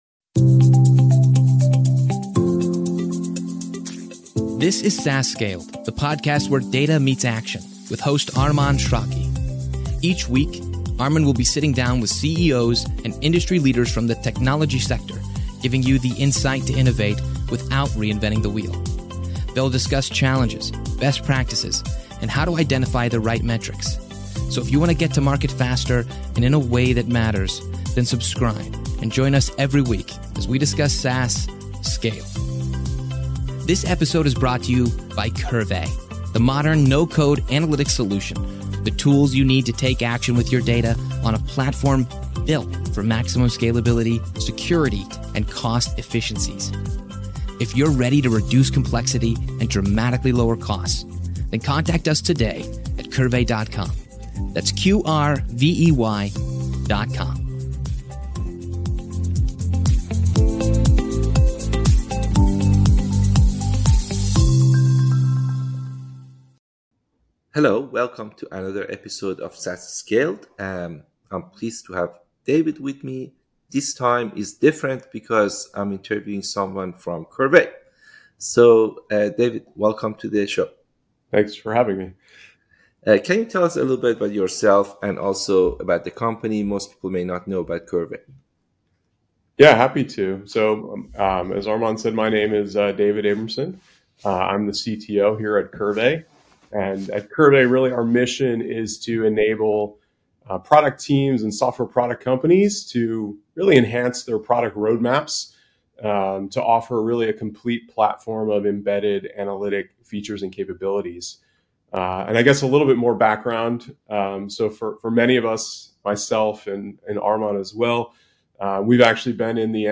SaaS Scaled - Interviews about SaaS Startups, Analytics, & Operations